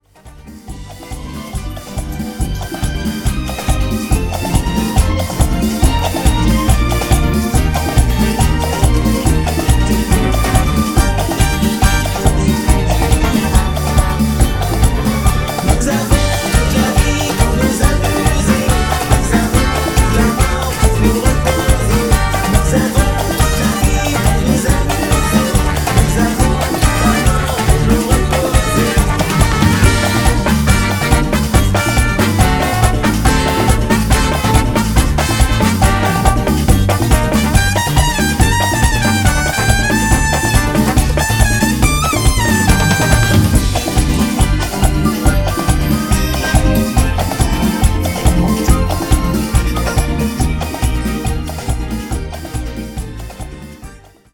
Version Big-Band Jazz